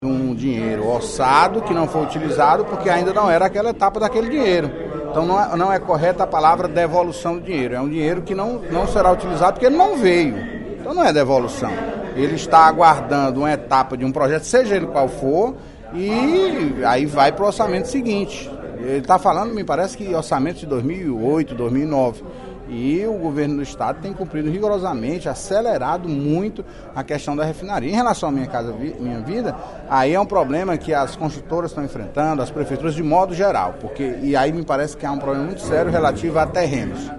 O deputado Antonio Carlos (PT) afirmou, na sessão plenária da Assembleia Legislativa desta quarta-feira (15/02), que os recursos federais destinados às obras do Programa de Aceleração do Crescimento (PAC) no Ceará não foram perdidos porque deixaram de ser aplicados no ano passado.